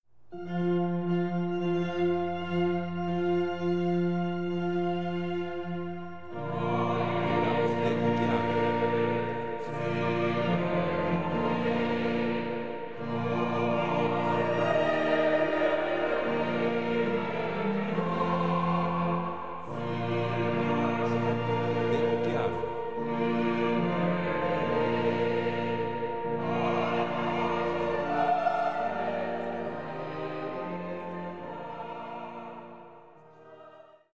músicas para casamentos